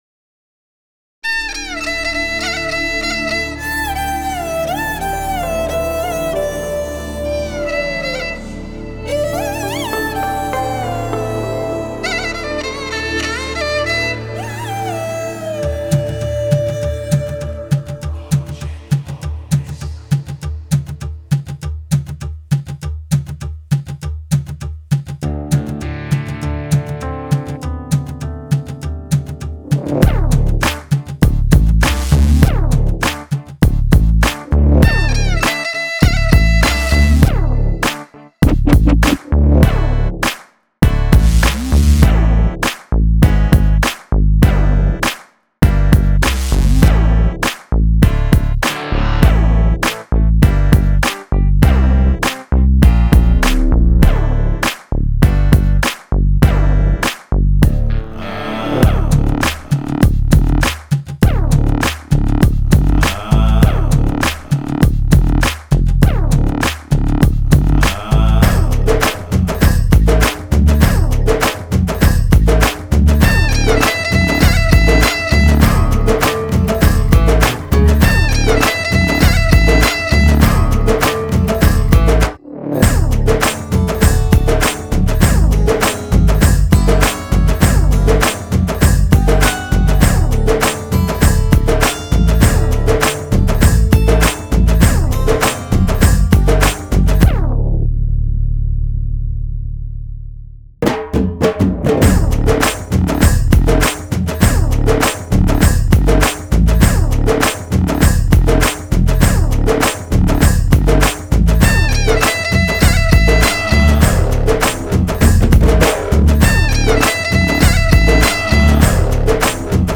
Singer: Instrumental